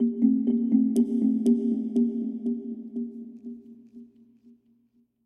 Мелодия для гипноза может повторяться